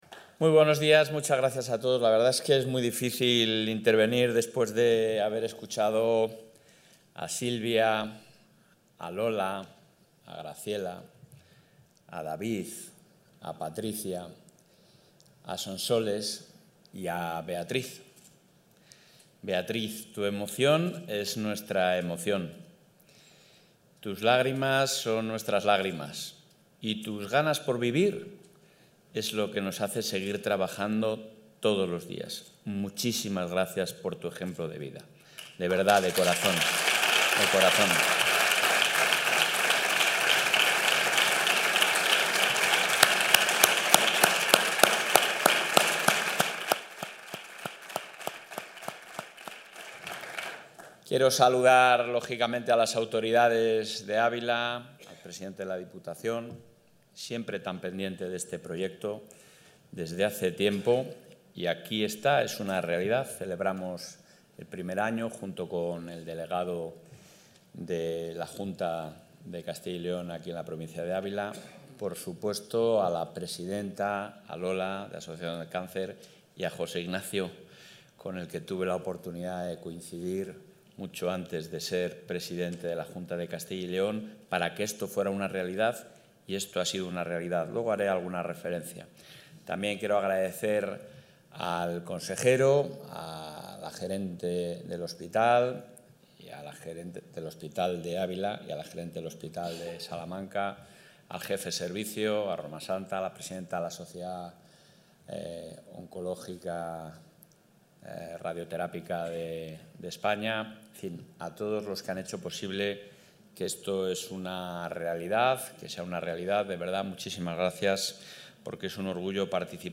El presidente de la Junta de Castilla y León, Alfonso Fernández Mañueco, ha participado hoy en la jornada científica con...
Intervención del presidente de la Junta.